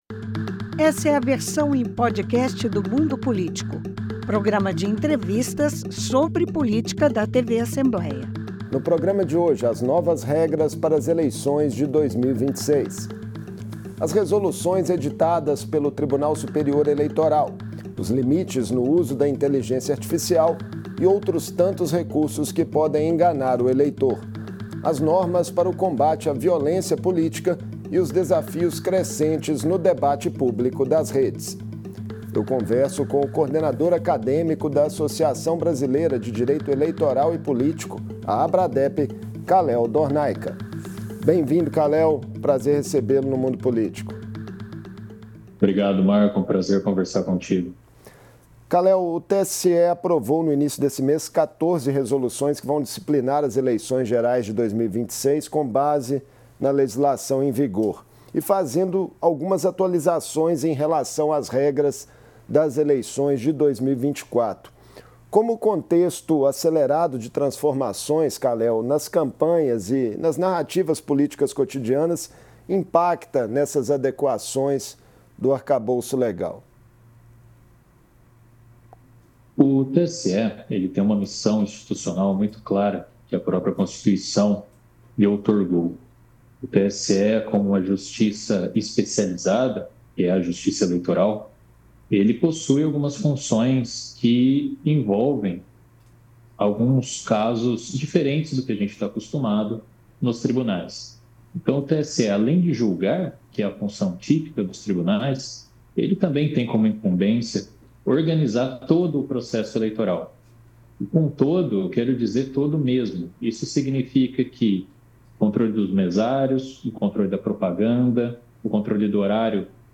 O Tribunal Superior Eleitoral anunciou no mês de março as novas regras para as eleições gerais de outubro. Em entrevista